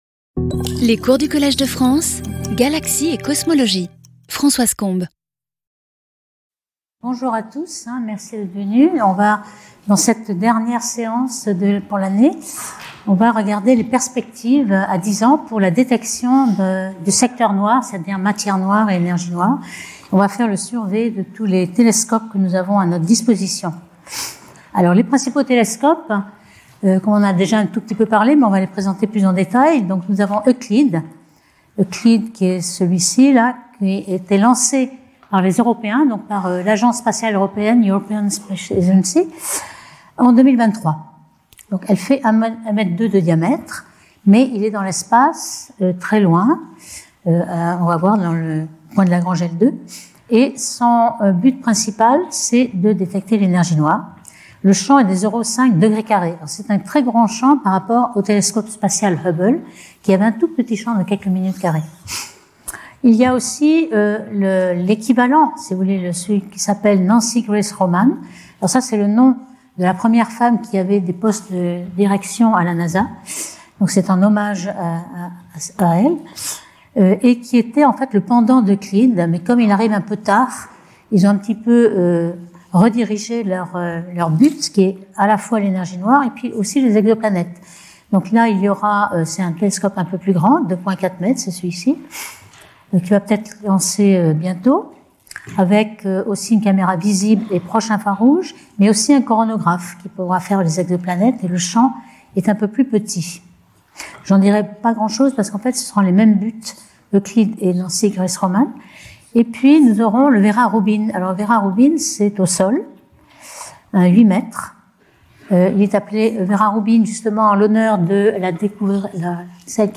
Speaker(s) Françoise Combes Professor at the Collège de France
Lecture